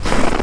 STEPS Snow, Walk 10-dithered.wav